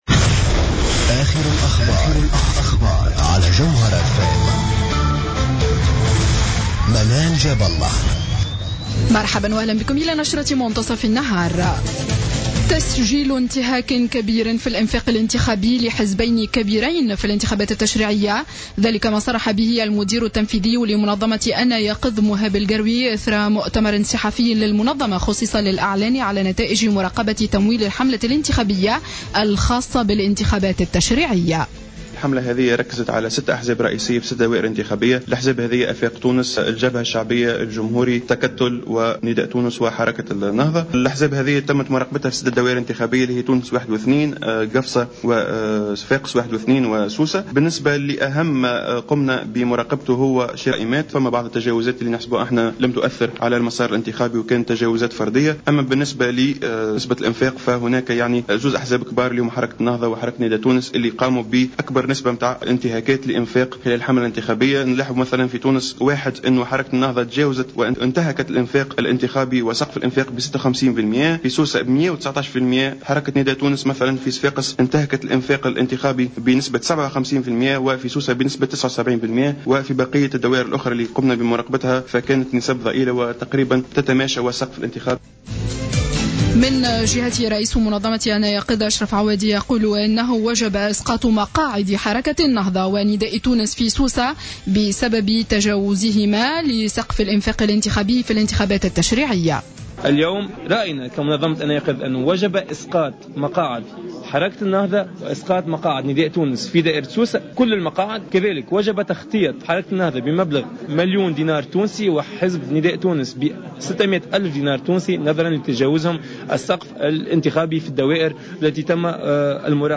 نشرة أخبار منتصف النهار ليوم الاثنين 15-12-14